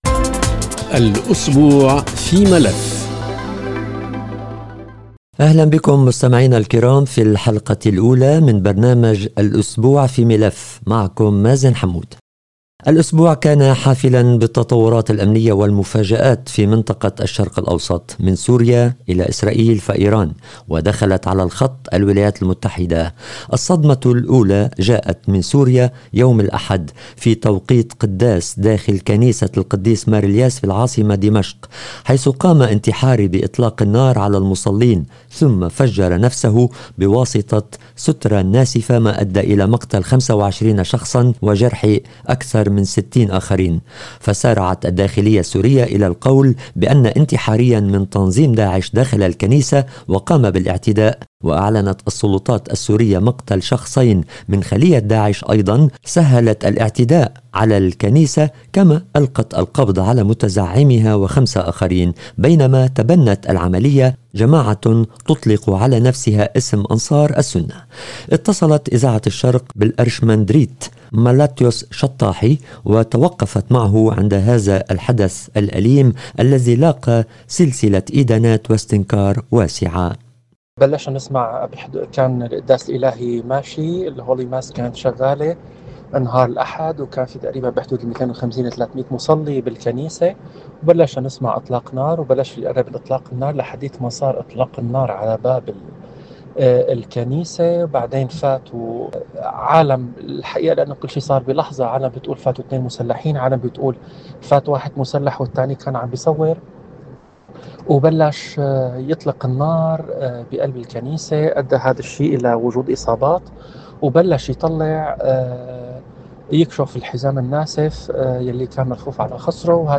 في حلقة هذا الاسبوع عودة على ابرز الاحداث السياسية والامنية في الشرق الاوسط مع عدد من الباحثين والاكاديميين ورجال الدين.